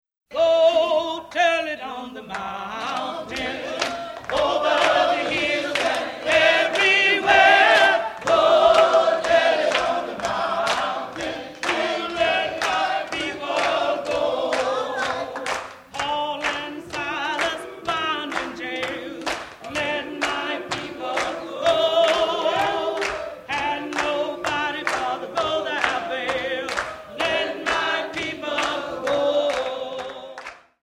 This song was recorded in Greenwood, Mississippi as a mass meeting with the songleading function carried out by a core of singers led by Fannie Lou Hamer.